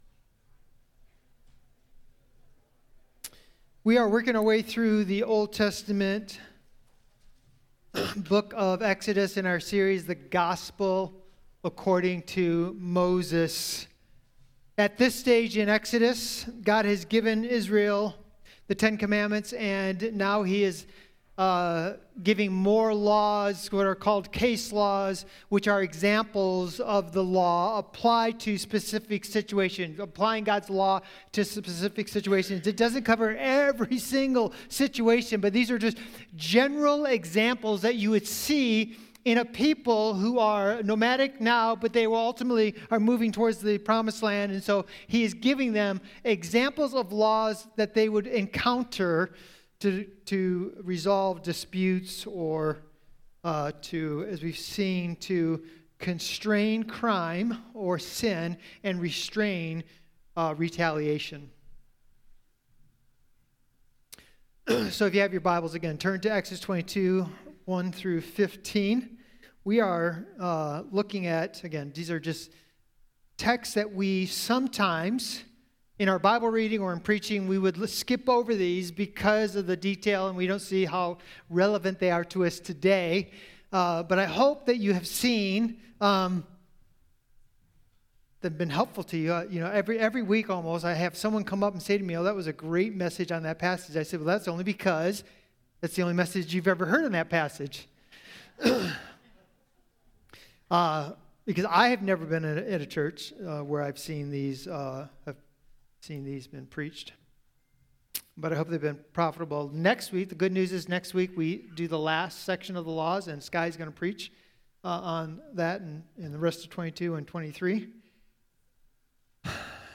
Sermons | Christian Community Church